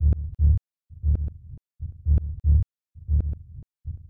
• TECHNO BASS 4 117 BPM.wav
TECHNO_BASS_4_117_BPM_sJp.wav